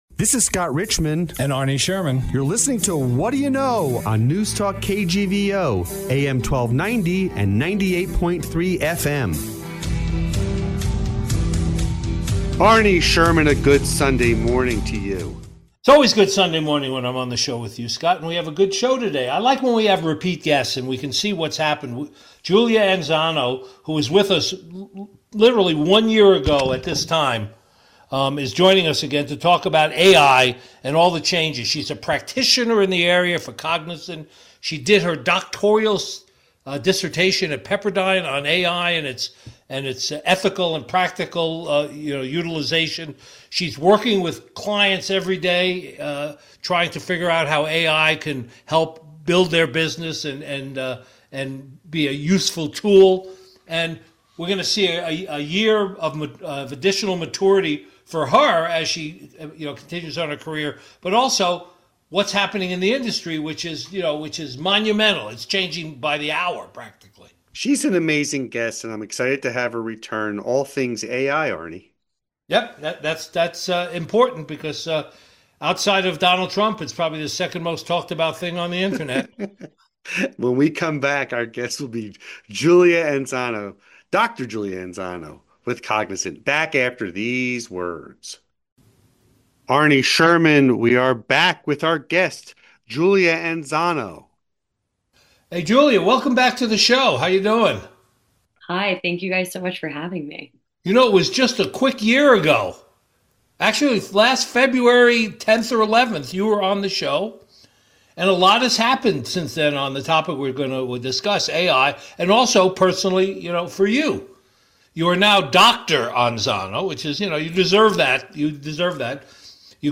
What Do you Know? is a weekly interview show, airing on KGVO-AM in Missoula, MT.